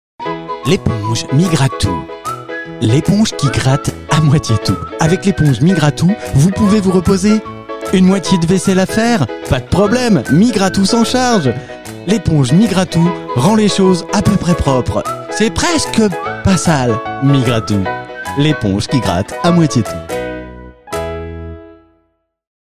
Fausses Pubs RADAR parodies publicités Fausses pubs